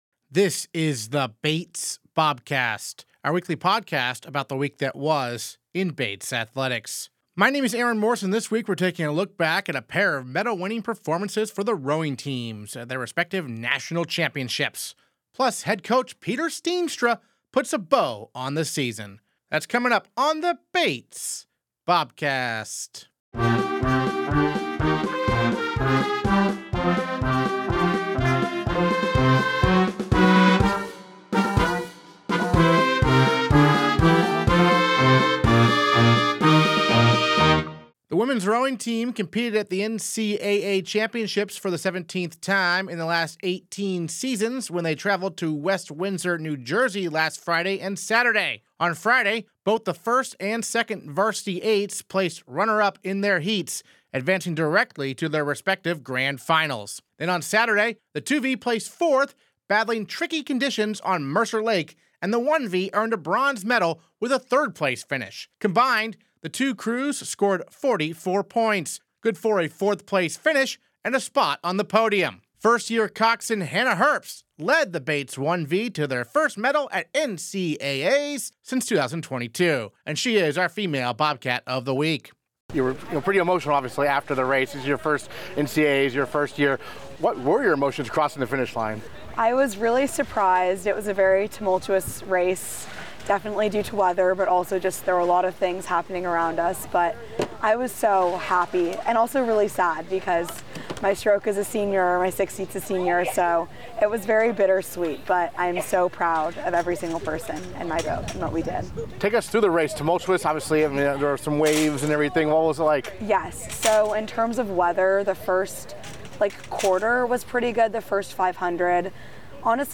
Interviews this episode